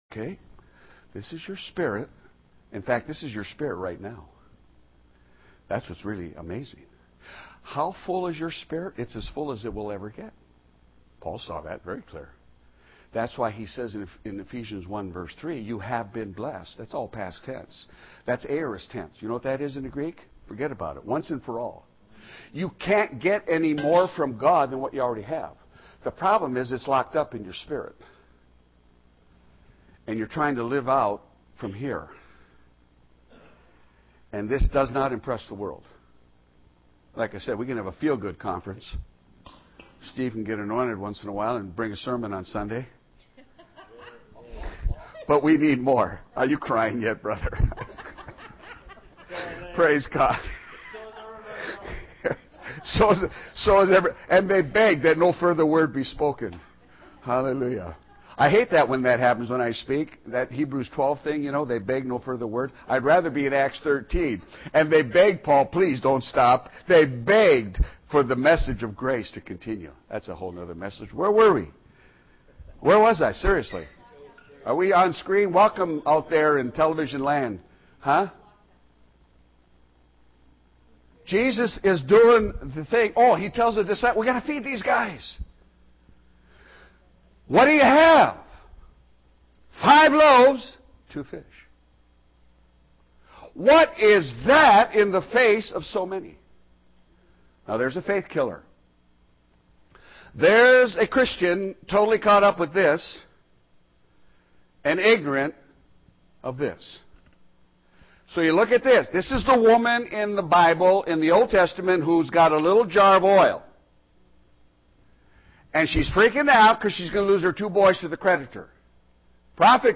2012 Prophetic Conference Session 2
Teaching